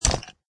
icestone.mp3